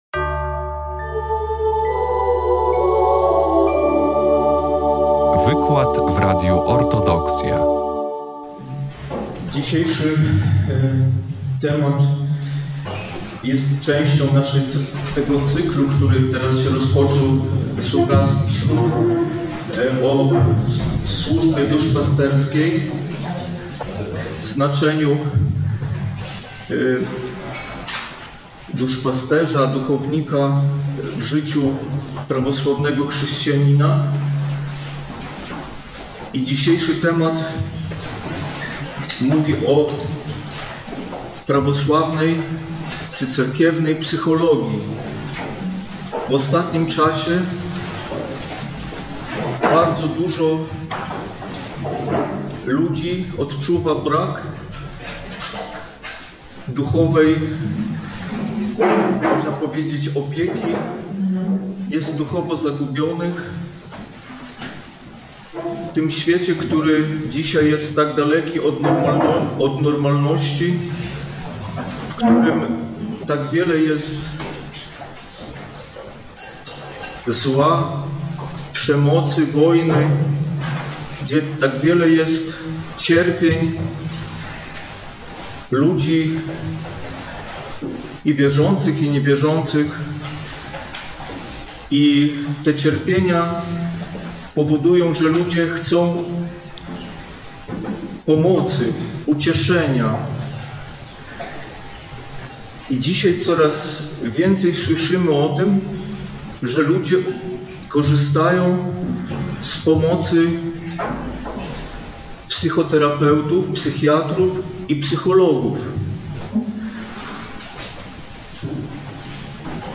Wykład został nagrany 13 listopada 2024 roku w ramach cyklu Supraskie Środy.